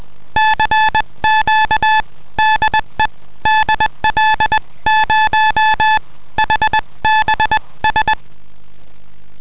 Tastfunk (CW)
Klangbeispiel Telegrafie: